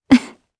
Ripine-Vox_Laugh_jp.wav